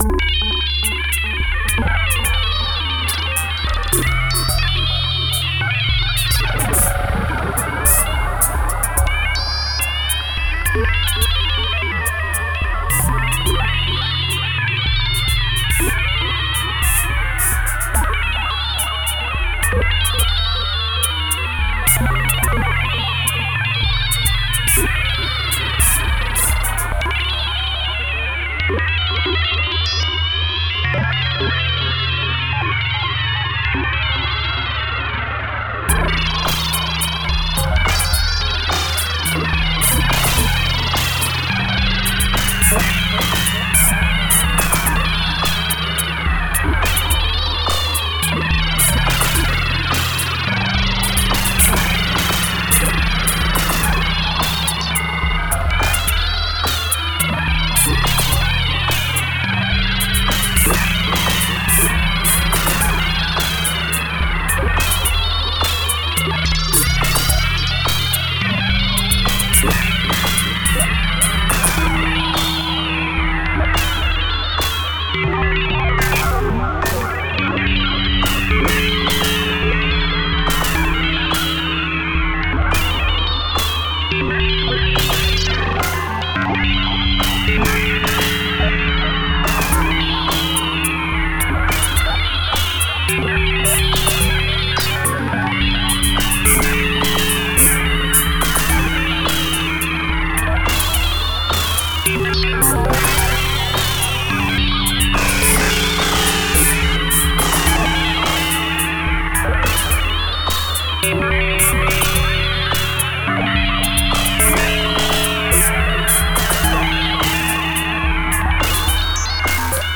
Genre IDM